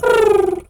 pigeon_2_call_calm_08.wav